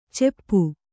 ♪ ceppu